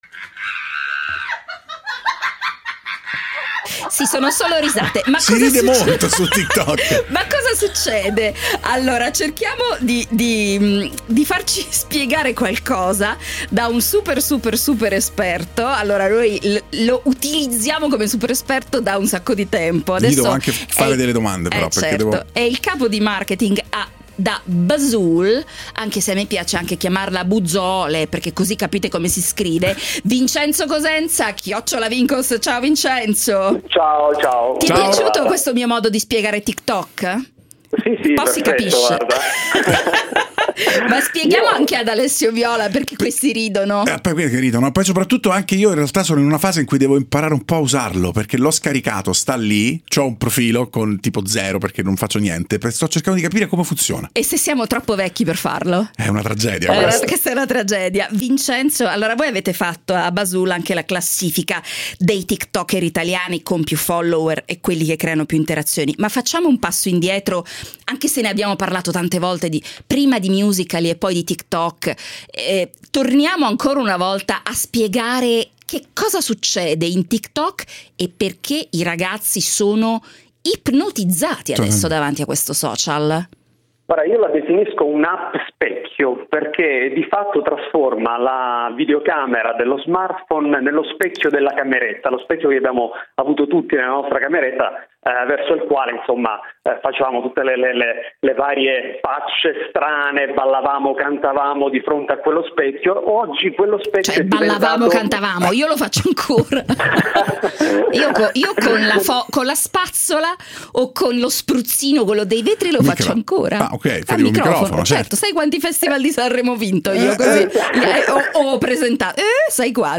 A Radio24 per parlare di TikTok